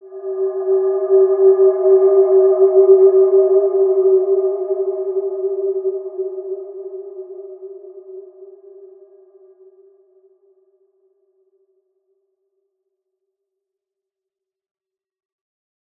Wide-Dimension-G3-mf.wav